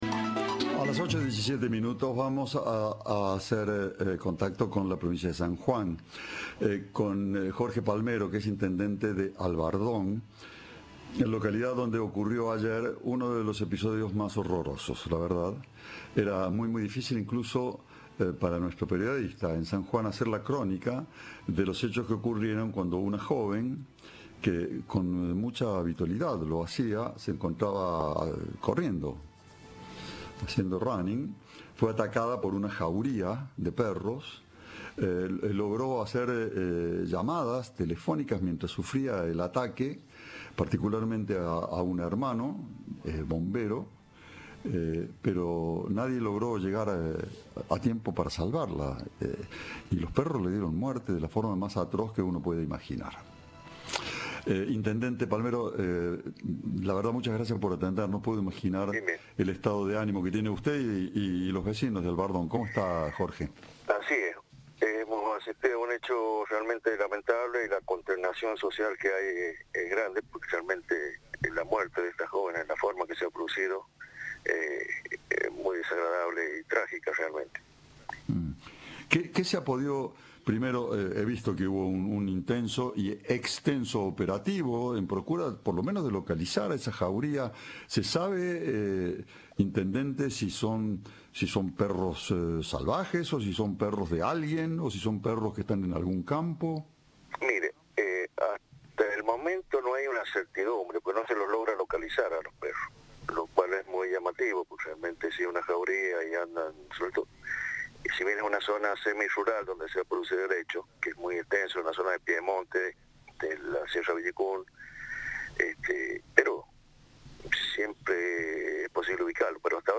El intendente Jorge Palmero dijo a Cadena 3 que todavía no logran ubicar a los perros.
Entrevista